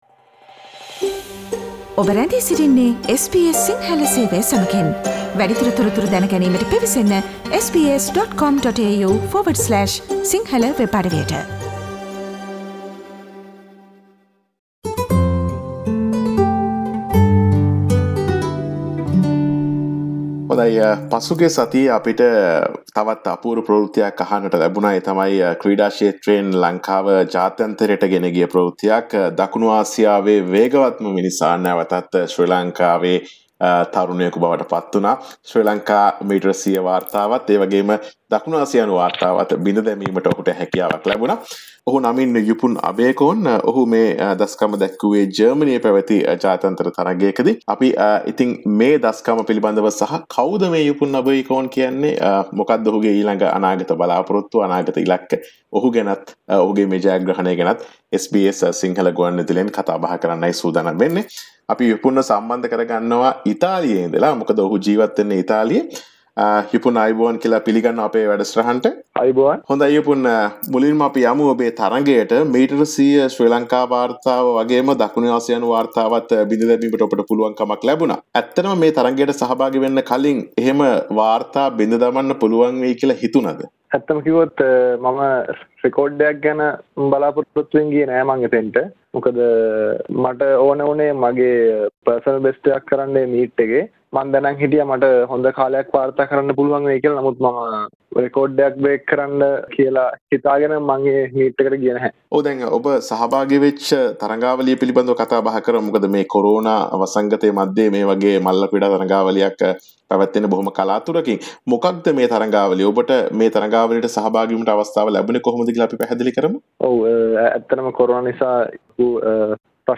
Yupun Abeykoon, the fastest man in South Asia, speaks to SBS Sinhala Radio
Yupun Abeykoon on 09 September 2020 smashed the 100m Sri Lankan and South Asian record with a sizzling 10.16 seconds in an international meeting held in Germany. Yupun speaks about his achievement with SBS Sinhala Radio.